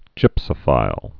(jĭpsə-fīl)